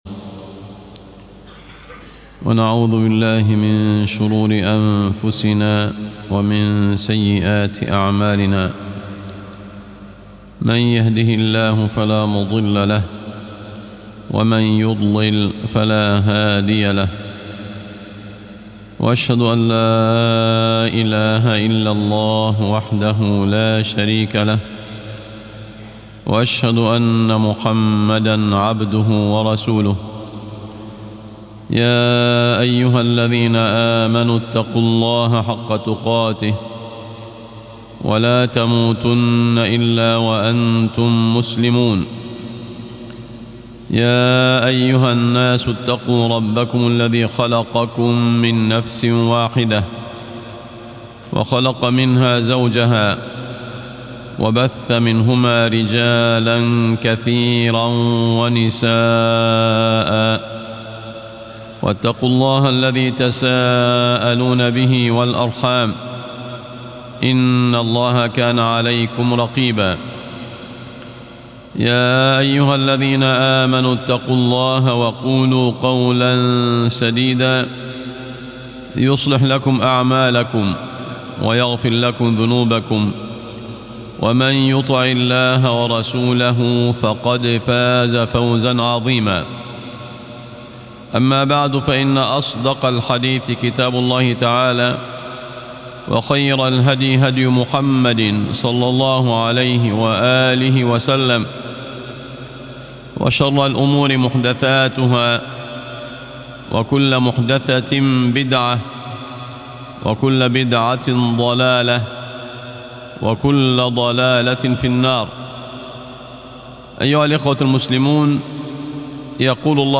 مفهوم العمل الصالح - خطب الجمعة